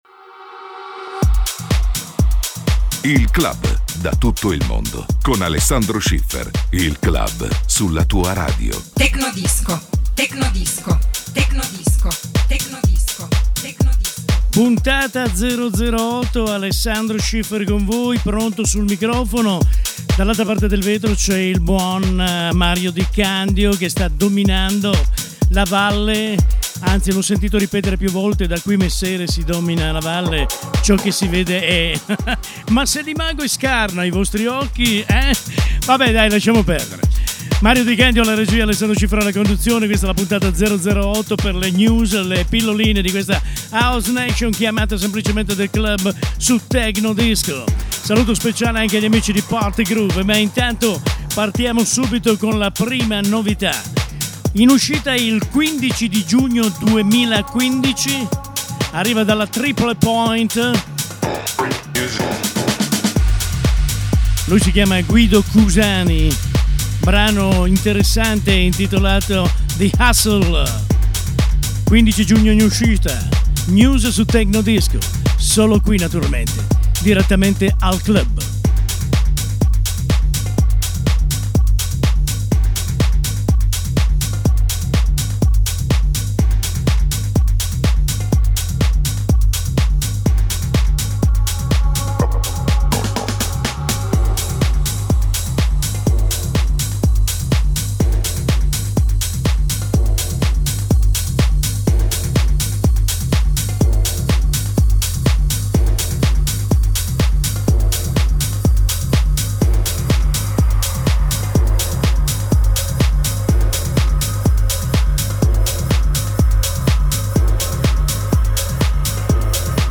che presenta ogni settimana alcune novità House e Techno.